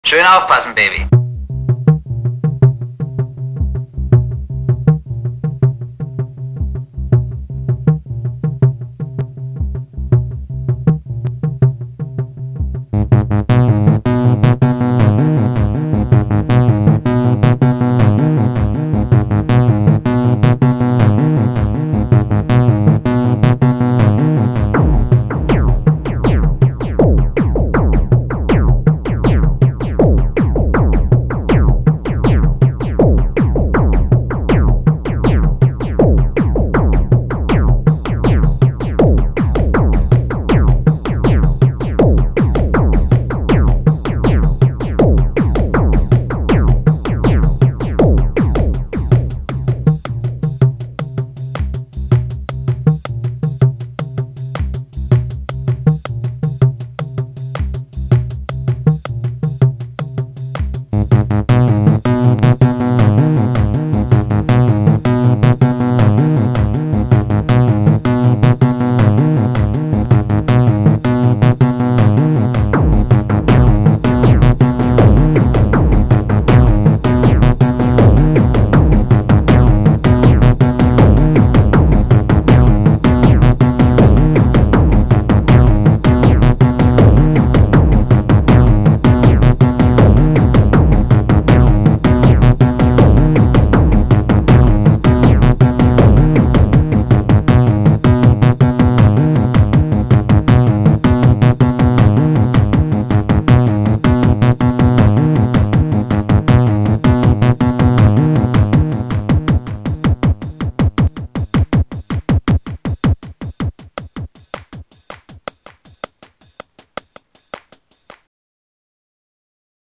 [Edit] Ha! Ich habe tatsächlich noch zwei Lieder, die ich "live" gemacht und nebenher aufgenommen habe. Entsprechend eintönig klingt das Ganze.